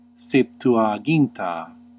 Ääntäminen
Ääntäminen Ecclesiastical: IPA: /sep.tu.aˈd͡ʒin.ta/ IPA: [sep.twaˈd͡ʒin.ta] Haettu sana löytyi näillä lähdekielillä: latina Käännöksiä ei löytynyt valitulle kohdekielelle.